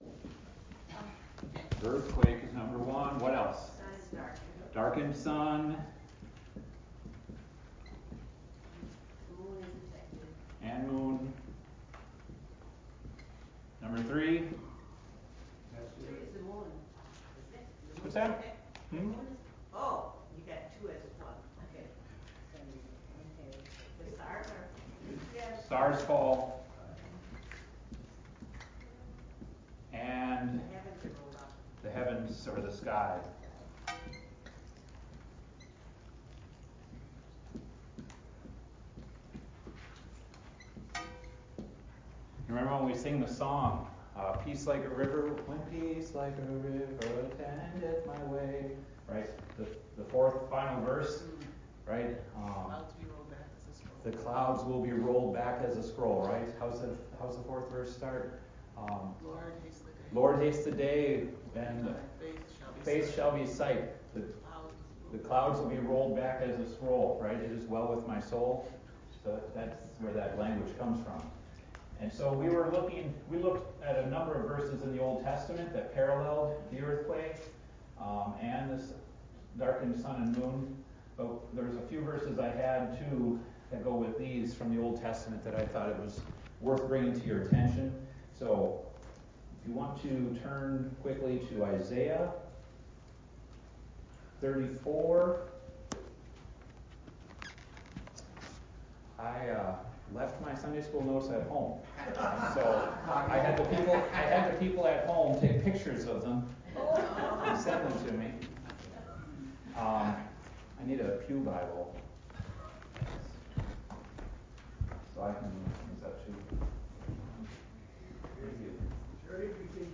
Adult Sunday School